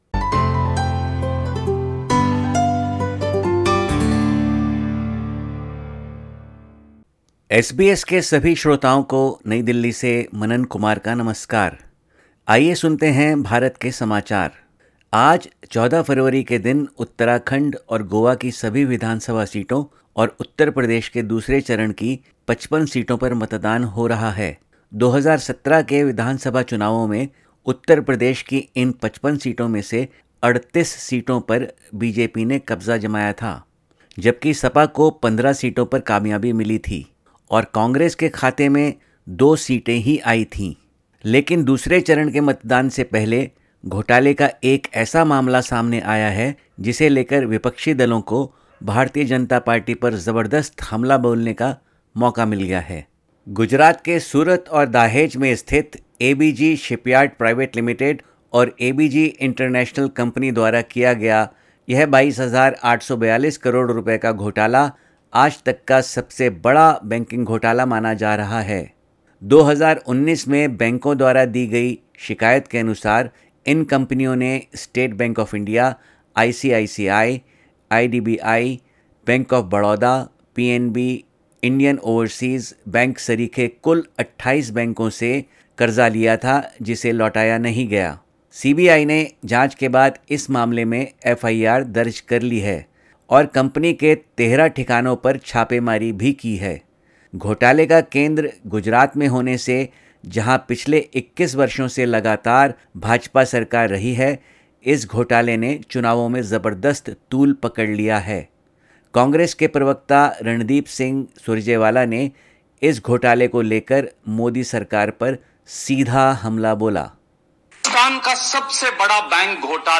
भारत के समाचार हिन्दी में